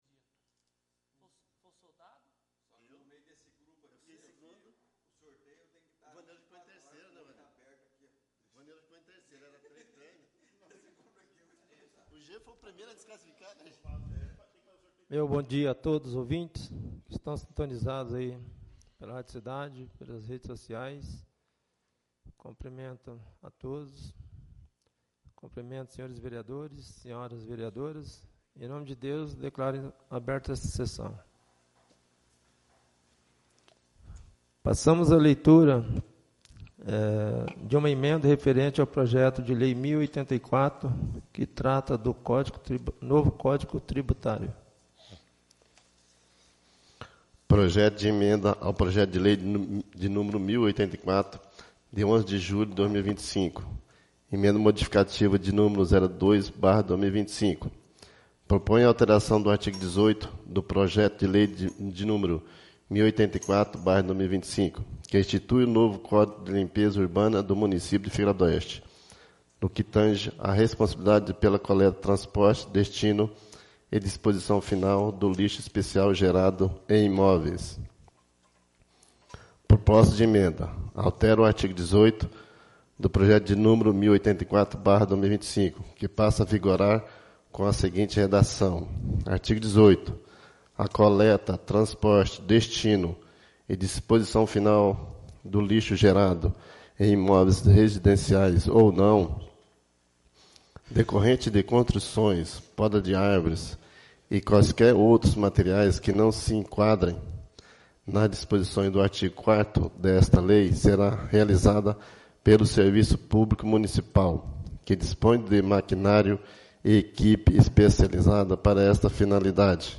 11° SESSÃO ORDINÁRIA DE 25 DE AGOSTO DE 2025